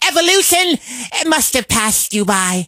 carl_kill_vo_04.ogg